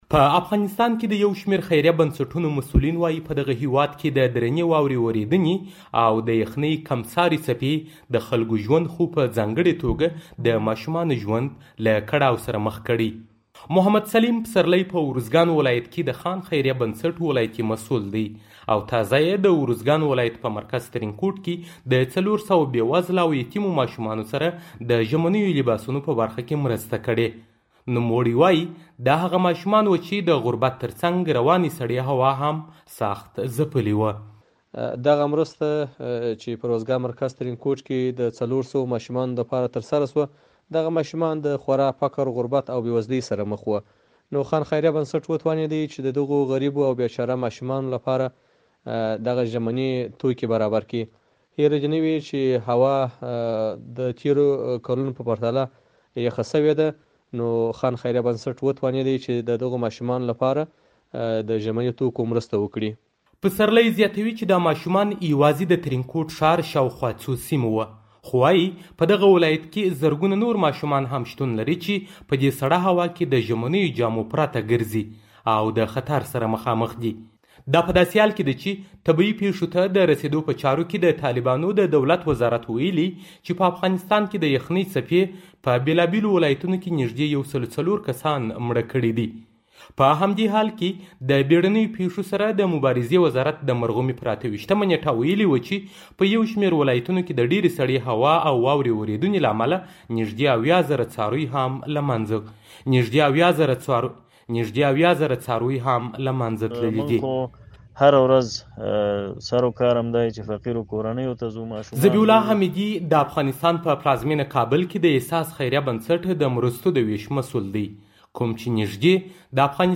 ماشومانو سره د مرستې راپور